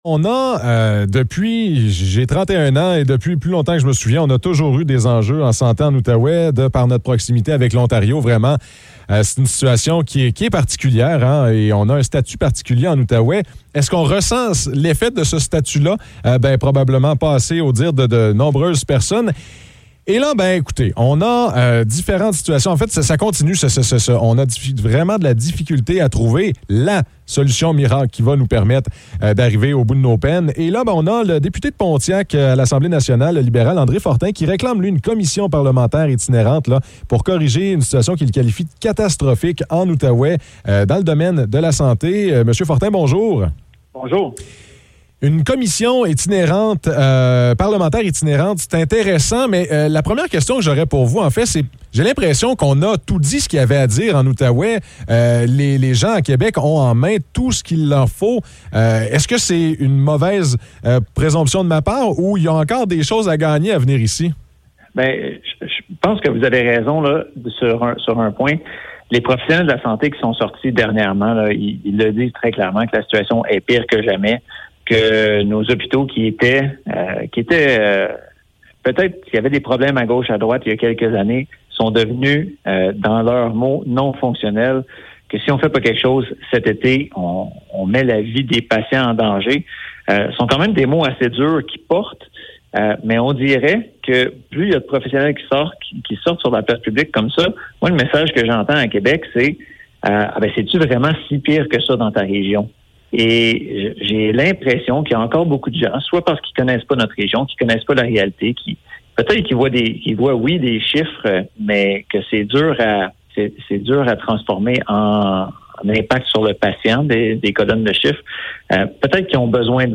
Entrevue avec André Fortin